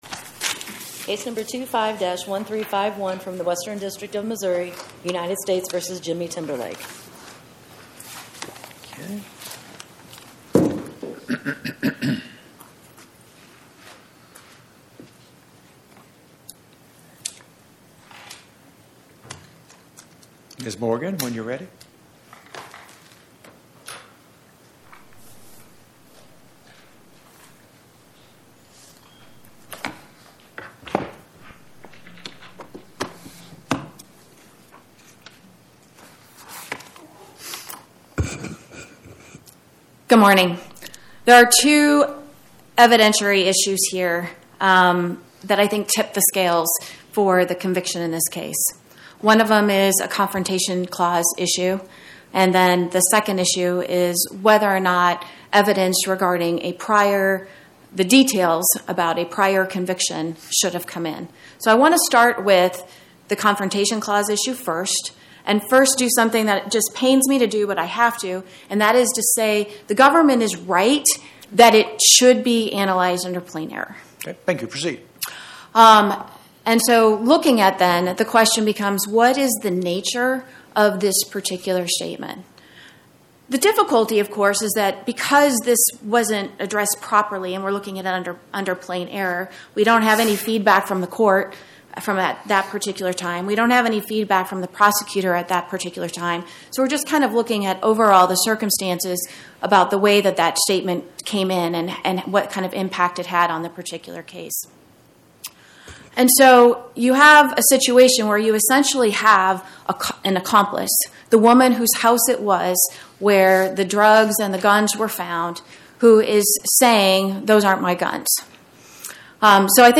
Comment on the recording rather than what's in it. Oral argument argued before the Eighth Circuit U.S. Court of Appeals on or about 01/16/2026